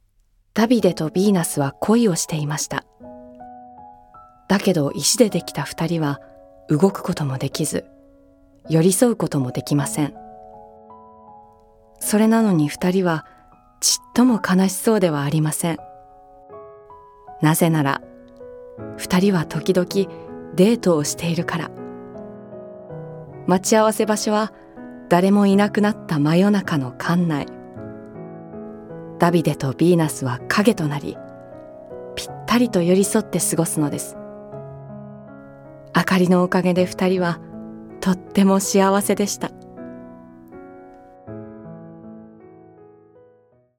ボイスサンプル
朗読